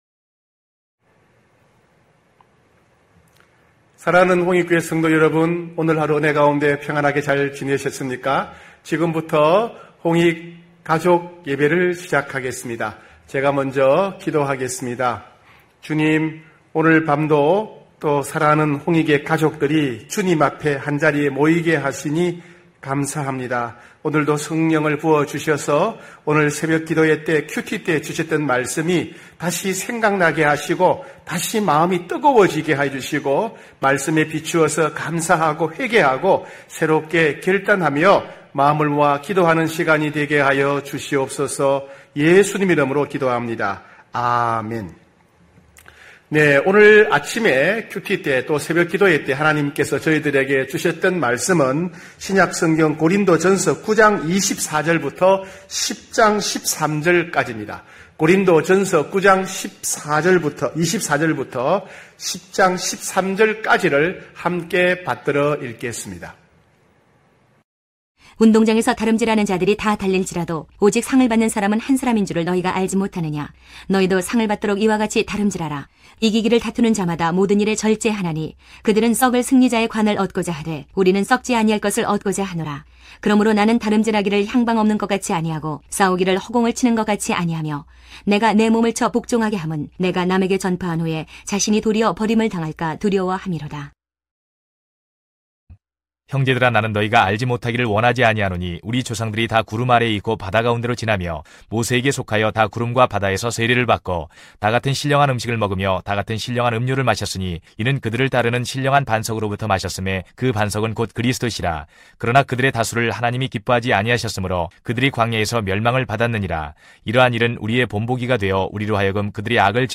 9시홍익가족예배(6월15일).mp3